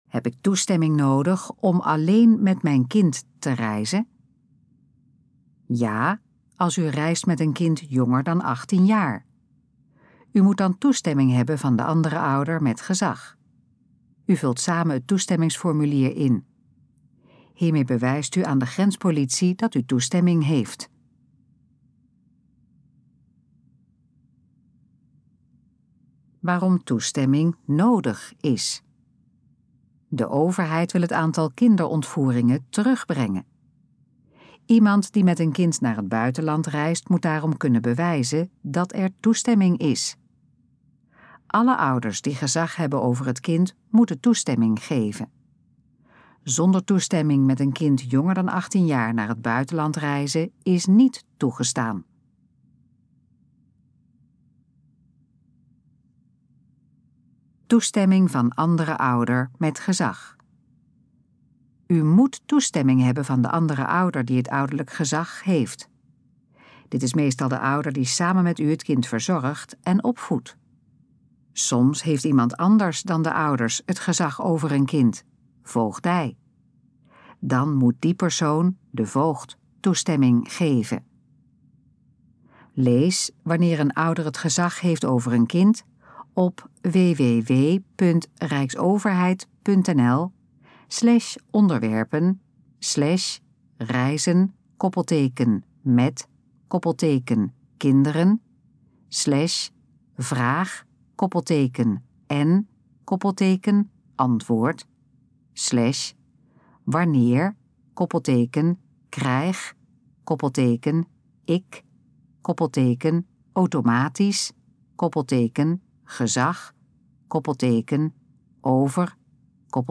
Gesproken versie van Heb ik toestemming nodig om alleen met mijn kind te reizen?
Dit geluidsfragment is de gesproken versie van de informatie op de pagina Heb ik toestemming nodig om alleen met mijn kind te reizen.